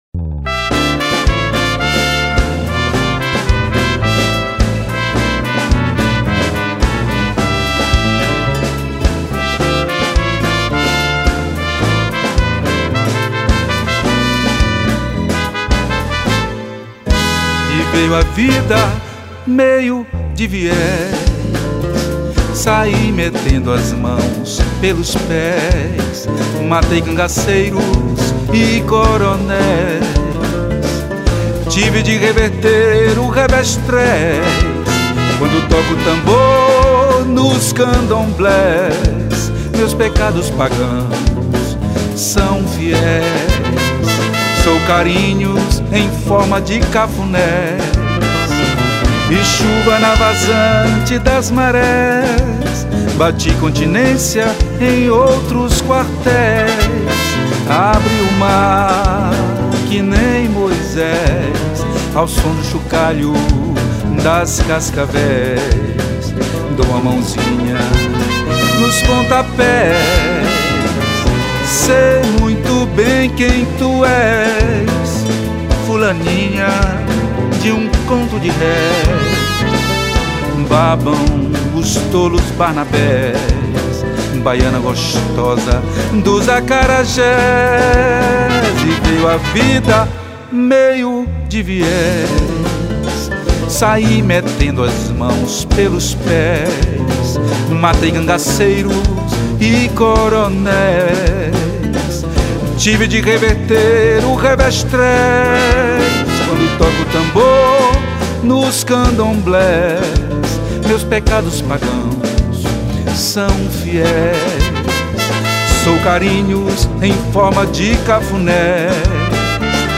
599   04:46:00   Faixa:     Frevo